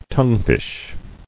[tongue·fish]